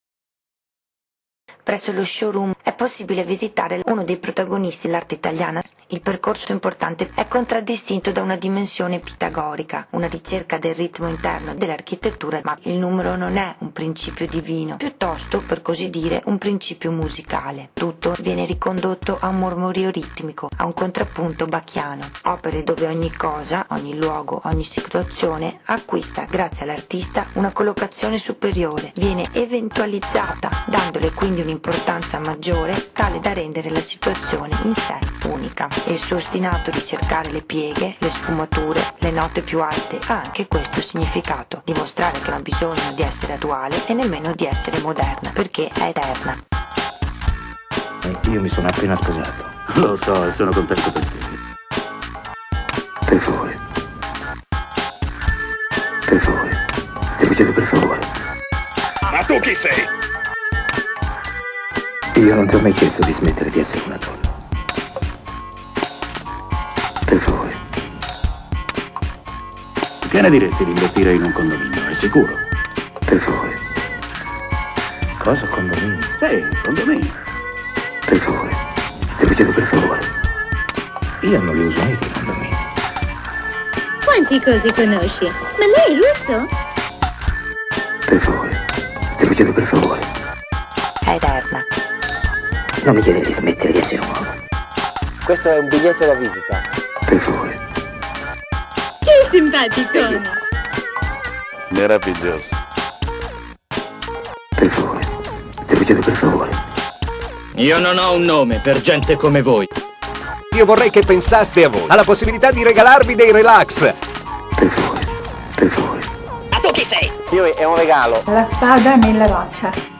Registrato live alla stazione di servizio di BorgoTaro